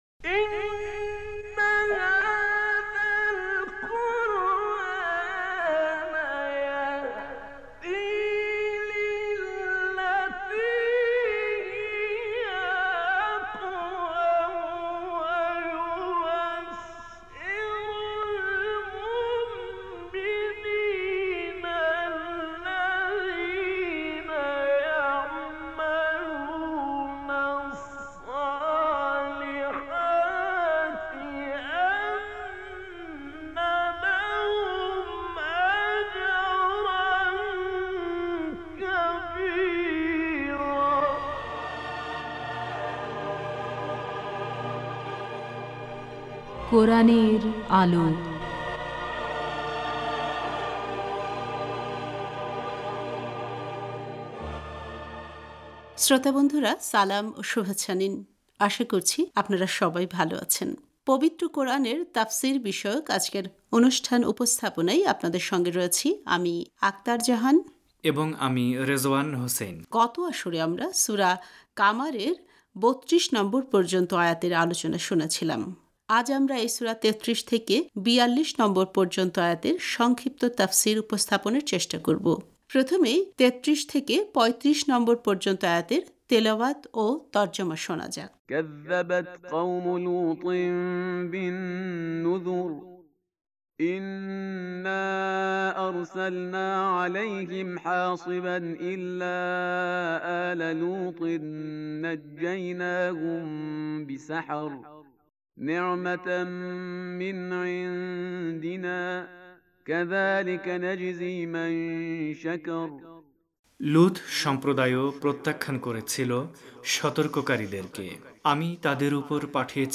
আজ আমরা এই সূরার ৩৩ থেকে ৪২ নম্বর পর্যন্ত আয়াতের সংক্ষিপ্ত তাফসির উপস্থাপনের চেষ্টা করব। প্রথমেই ৩৩ থেকে ৩৫ নম্বর পর্যন্ত আয়াতের তেলাওয়াত ও তর্জমা শোনা যাক